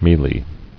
[meal·y]